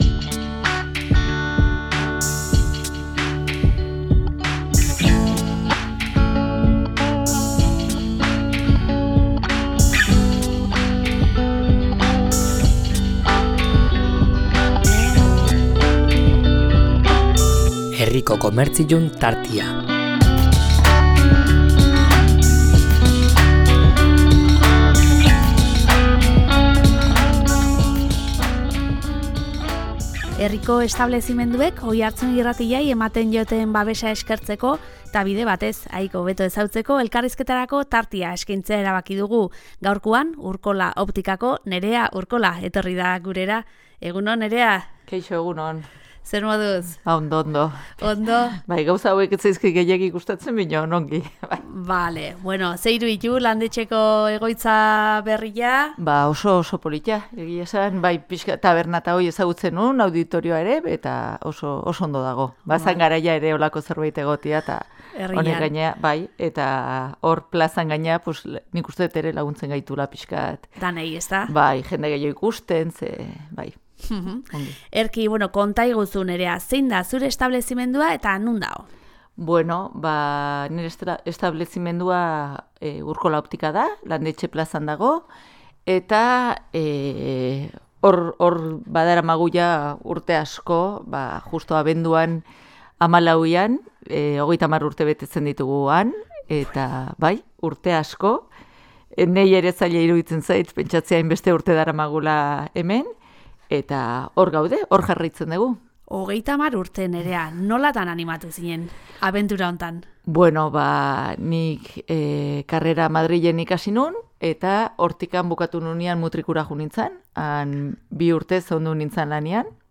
Herriko establezimenduek Oiartzun Irratiari ematen dioten babesa eskertzeko eta bide batez haiek hobeto ezagutzeko, elkarrizketarako tartea eskaintzea erabaki dugu.